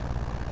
snd_boss_spellbreak.wav